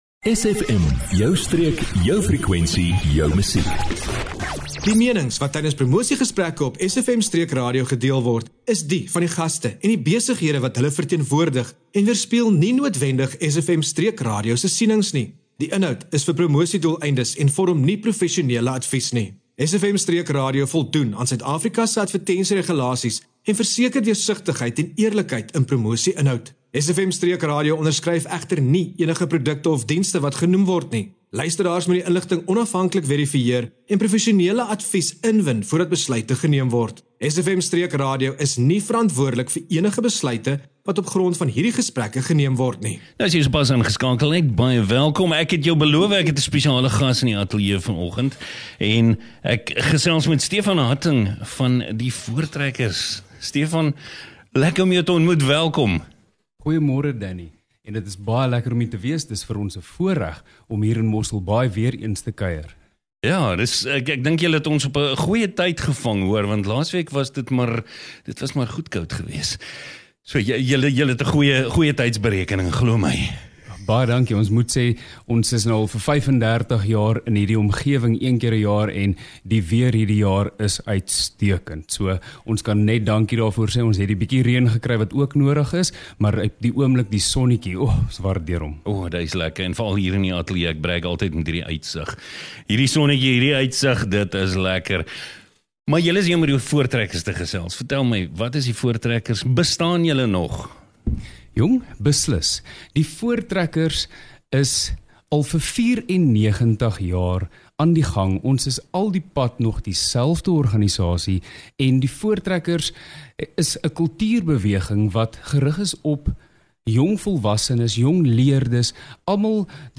🎙 Dis tyd vir nog die Voortrekker Seejol en ‘n inspirerende gesprek hier op SFM!
Vandag hoor jy direk van die span self: hoekom hulle by die Voortrekkers aangesluit het, wat hulle leer, en hoe jy deel kan word van hierdie lewende tradisie.